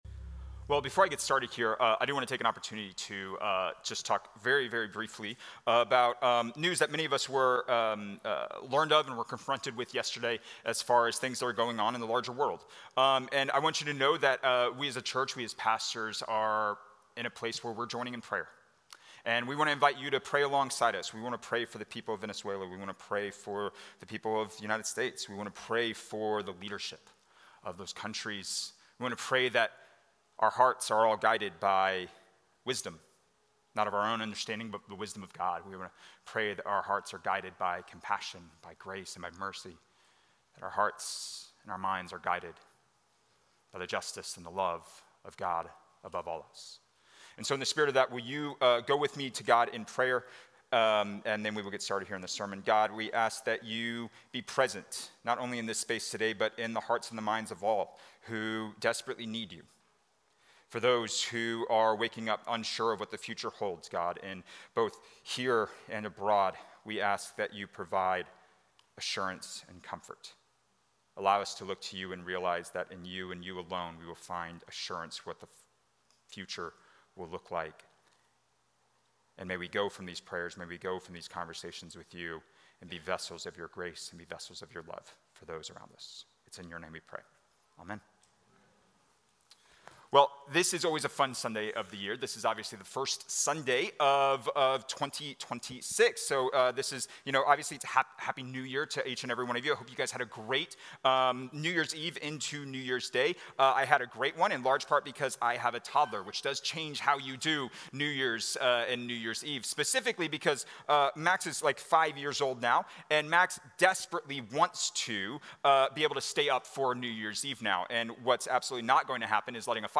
A message from the series "Advent."